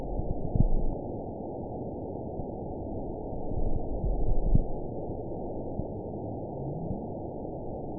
event 920160 date 03/01/24 time 22:32:09 GMT (1 year, 2 months ago) score 8.95 location TSS-AB06 detected by nrw target species NRW annotations +NRW Spectrogram: Frequency (kHz) vs. Time (s) audio not available .wav